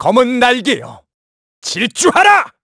Riheet-Vox_Skill7_kr_02.wav